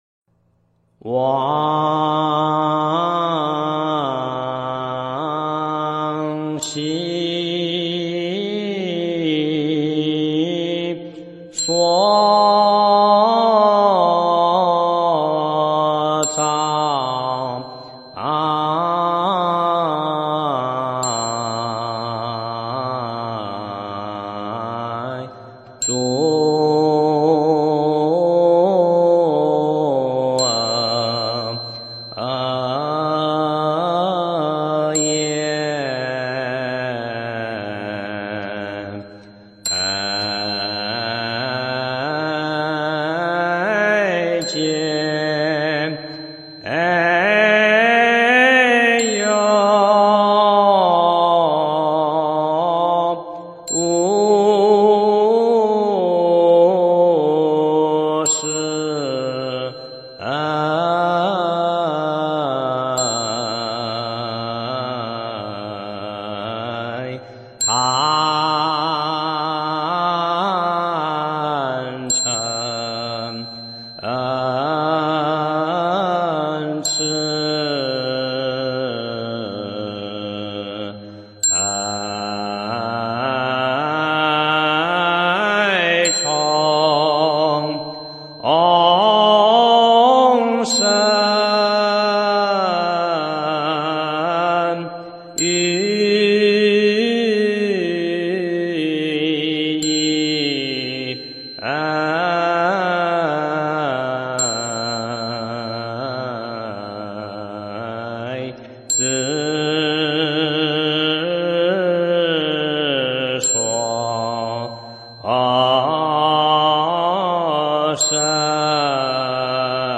忏悔文 唱颂
诵经
佛音 诵经 佛教音乐 返回列表 上一篇： 财宝天王心咒 下一篇： 大宝广博楼阁善住秘密陀罗尼咒 相关文章 那一年那一世--良人 那一年那一世--良人...